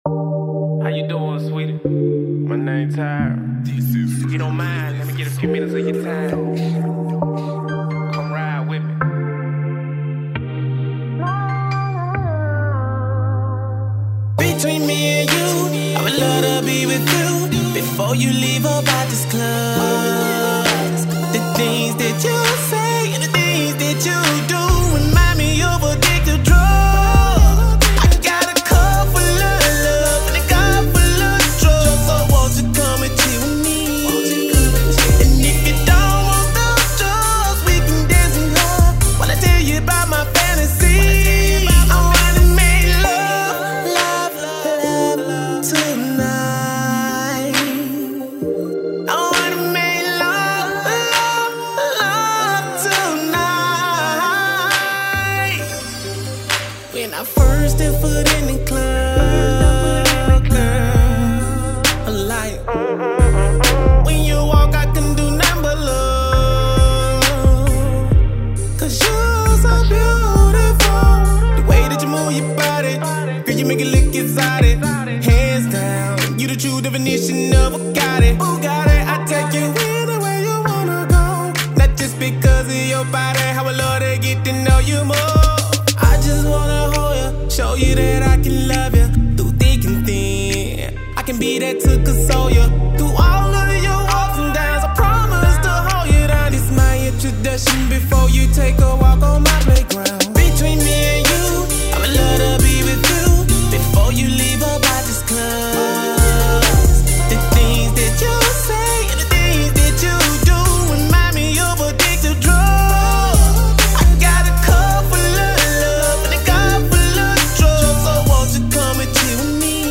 RnB
Birmingham RnB artist